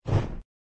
firepea.ogg